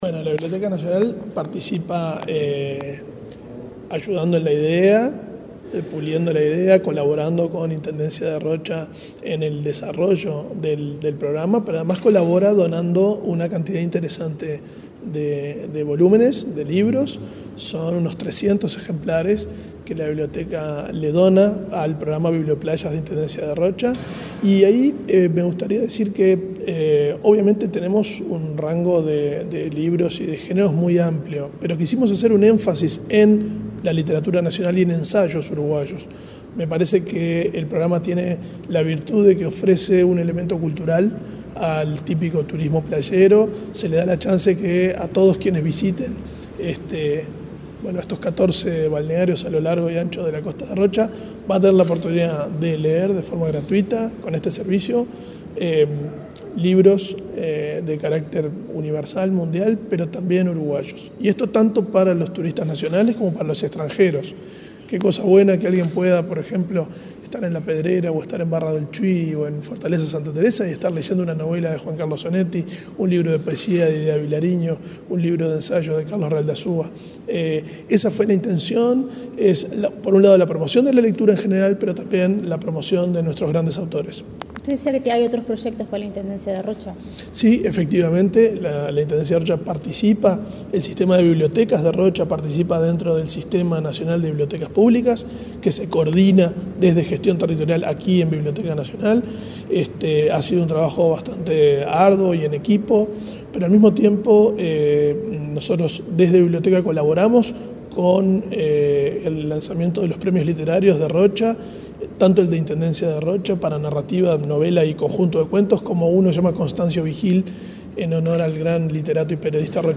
Entrevista al director de la Biblioteca Nacional, Valentín Trujillo